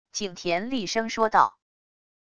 景田厉声说道wav音频
景田厉声说道wav音频生成系统WAV Audio Player